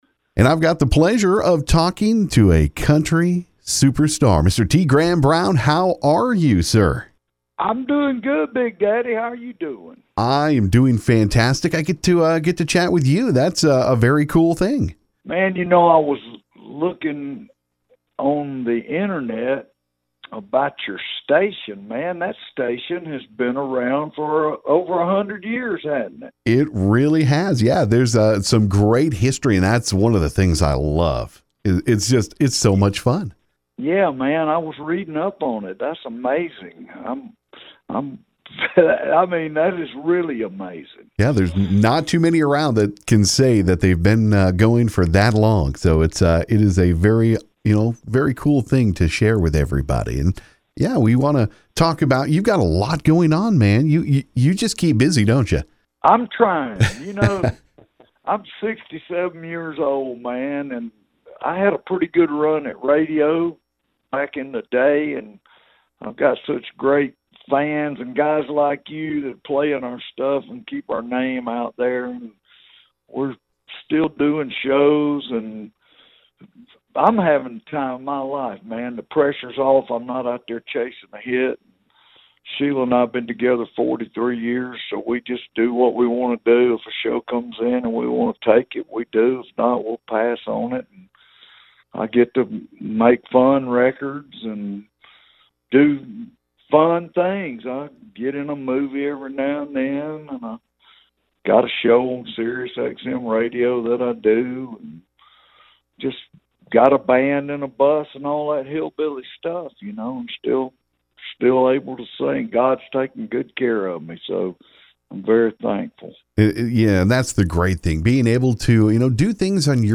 I got to talk with country star T. Graham Brown about his upcoming autobiography, his music new & old. Check out our recent chat…